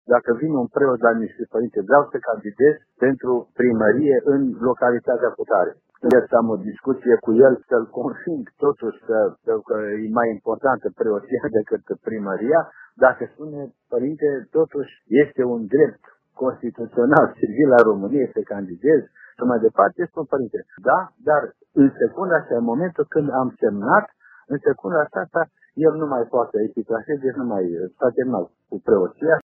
Preoţii care renunţă la a sluji în biserici pentru a deveni primari dar nu obţinut mandatul nu se mai pot întoarce la preoţie. Precizarea a fost făcută pentru Radio Timişoara de Înalt PreaSfinţia Sa Ioan Selejan, Mitropolitul Banatului. 05 Ioan Selejan